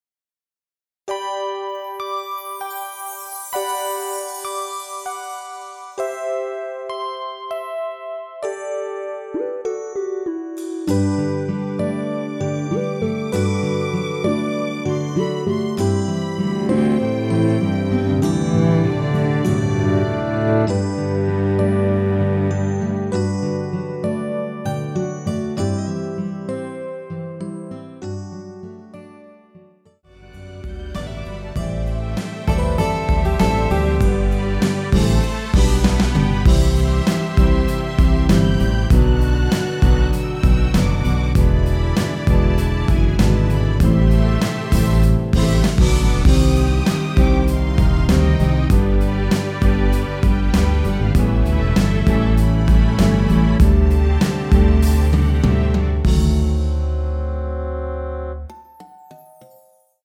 원키에서(-6)내린 MR입니다.
앞부분30초, 뒷부분30초씩 편집해서 올려 드리고 있습니다.
중간에 음이 끈어지고 다시 나오는 이유는